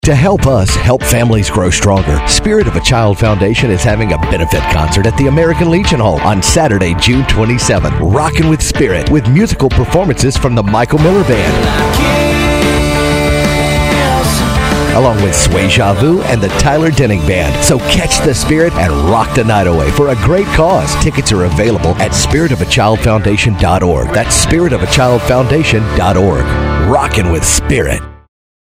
Cumulus Radio Commercial